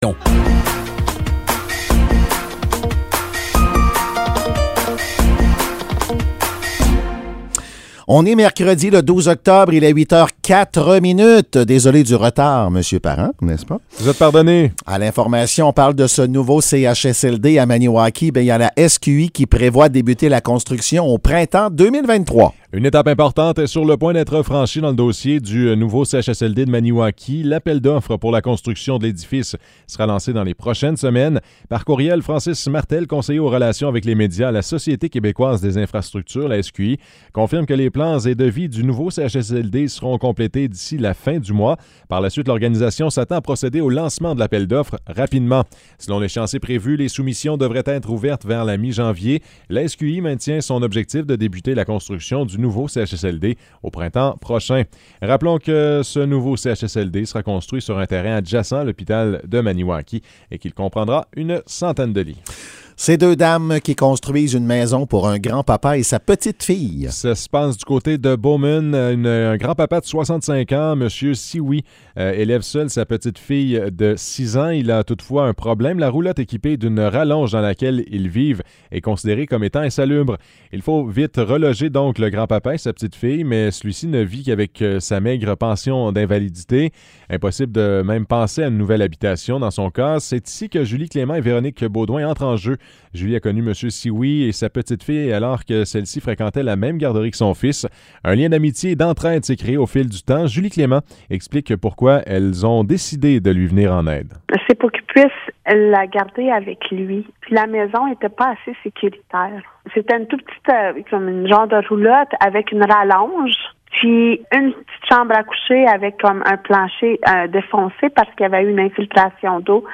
Nouvelles locales - 12 octobre 2022 - 8 h